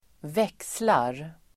Uttal: [²v'ek:slar]